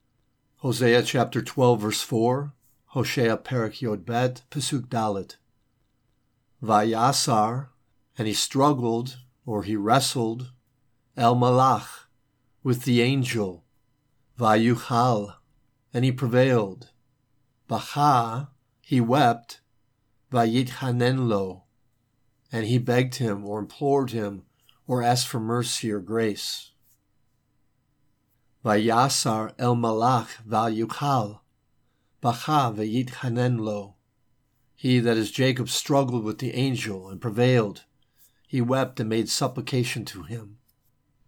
Hosea 12:4a Hebrew